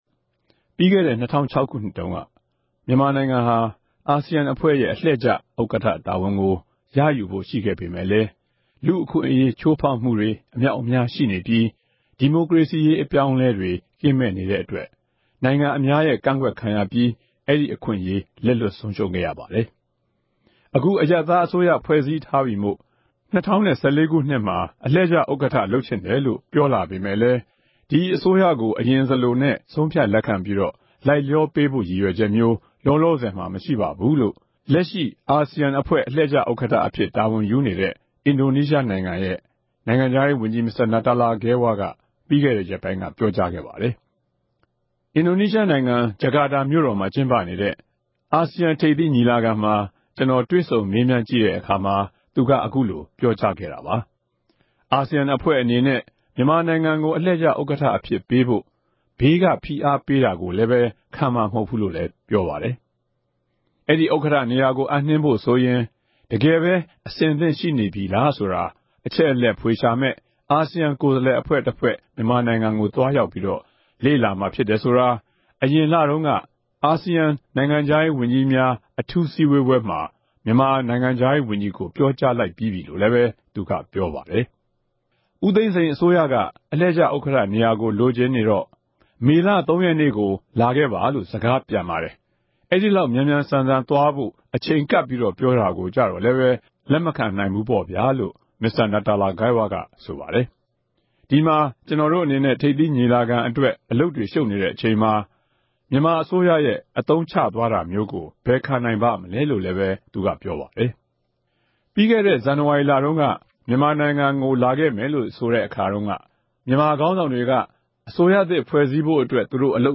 ဘာသာပြန်ဆို ဖတ်ကြားထားပါတယ်။